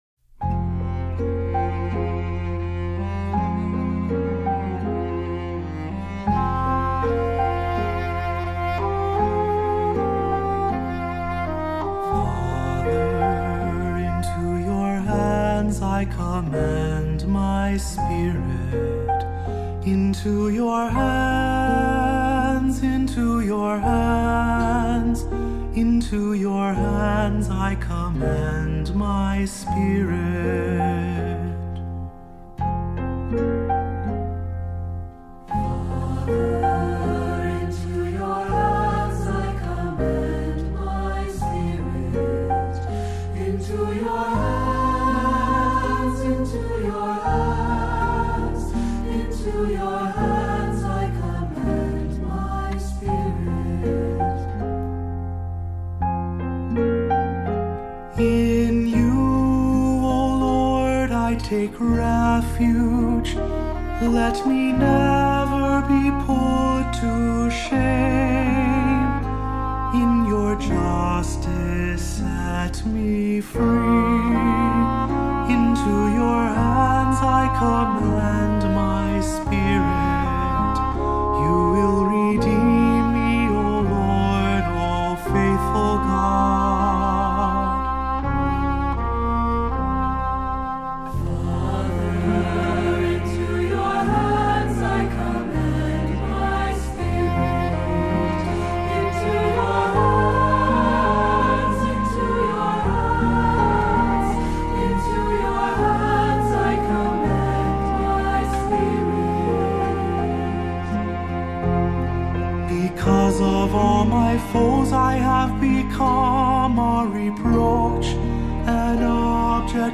Voicing: SAB, descant, assembly, cantor